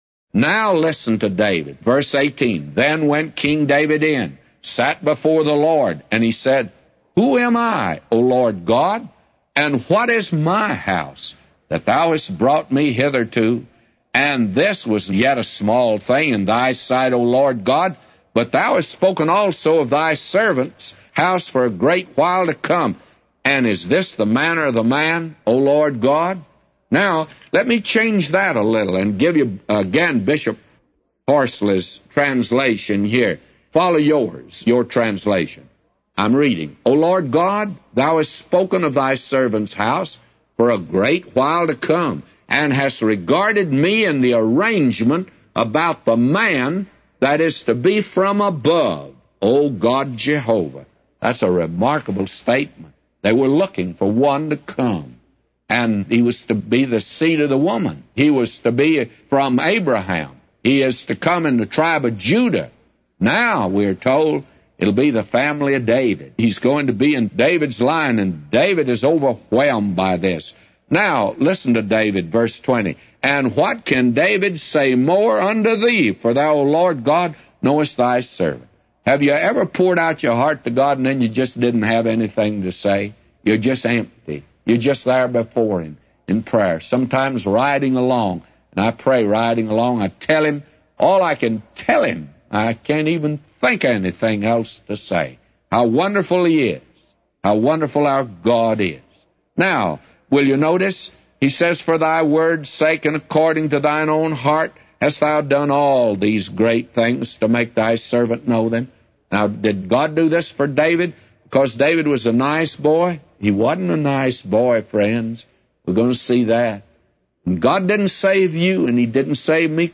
A Commentary By J Vernon MCgee For 2 Samuel 7:18-999